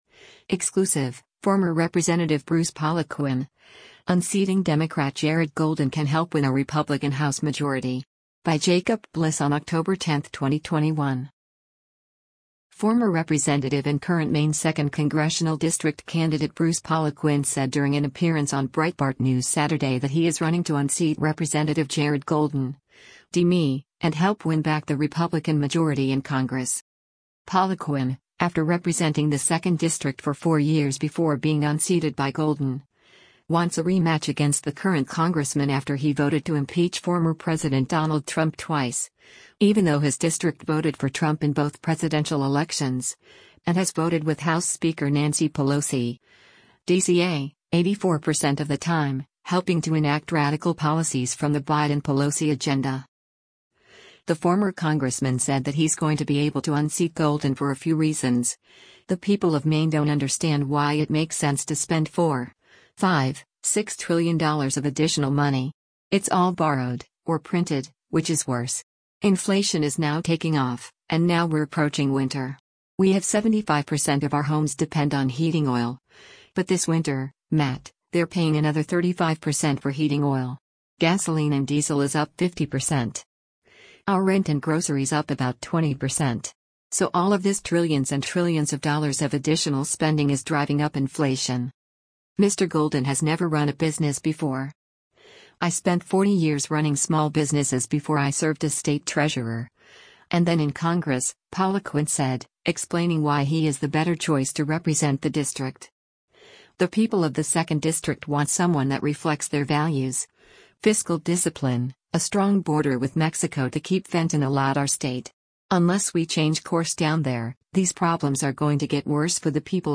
Former representative and current Maine Second Congressional District candidate Bruce Poliquin said during an appearance on Breitbart News Saturday that he is running to unseat Rep. Jared Golden (D-ME) and help win back the Republican majority in Congress.
Breitbart News Saturday airs on SiriusXM Patriot 125 from 10:00 a.m. to 1:00 p.m. Eastern.